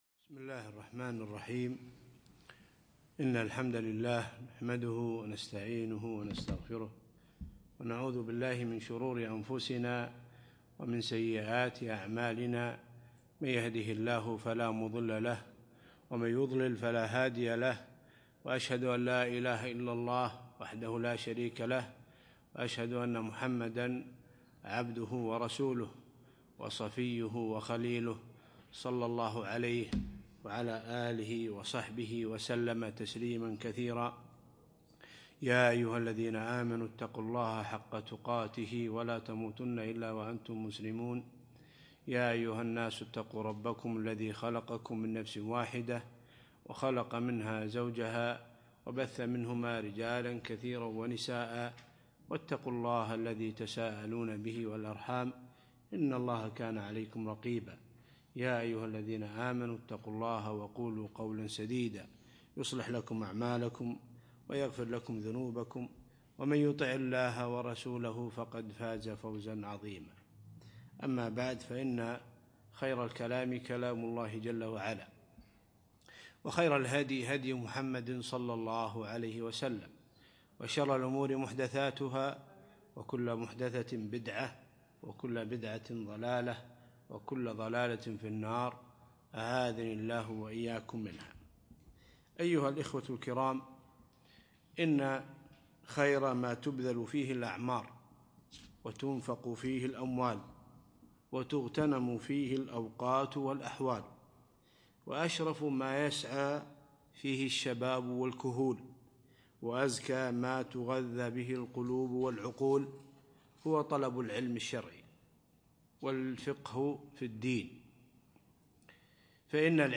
يوم الخميس 10 جمادى ثاني 1438 الموافق 9 3 2017 في مسجد مضحي الكليب العارضية